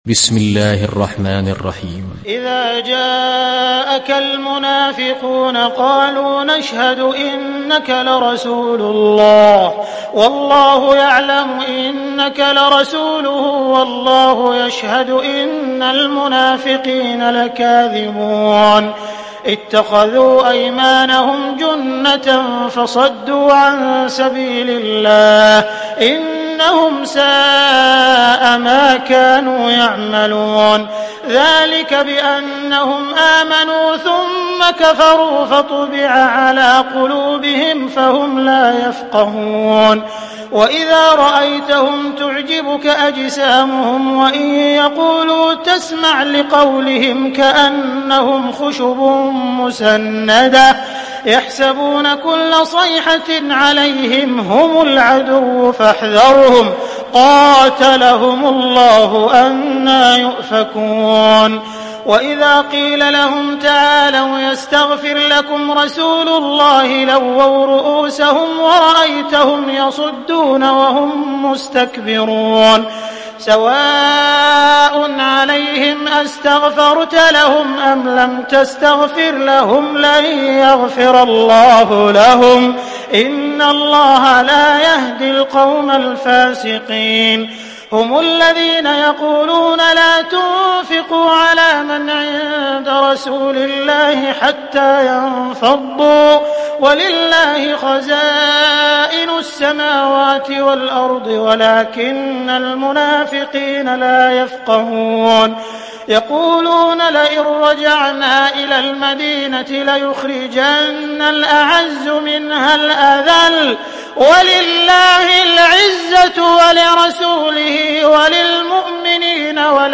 Surah Al Munafiqun Download mp3 Abdul Rahman Al Sudais Riwayat Hafs from Asim, Download Quran and listen mp3 full direct links